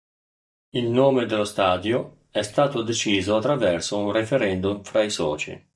(masculine) stadium (masculine) stage (masculine) phase Dažnis B2 Brūkšneliu surašyta kaip stà‧dio Tariamas kaip (IPA) /ˈsta.djo/ Etimologija (Anglų k.)